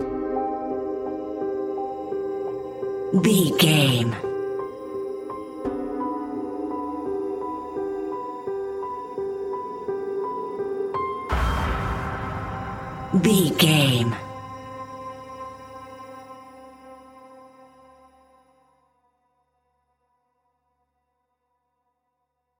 royalty free music
Thriller
Aeolian/Minor
Slow
ominous
dark
eerie
piano
synthesiser
horror music
Horror Pads